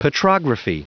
Prononciation du mot petrography en anglais (fichier audio)
Prononciation du mot : petrography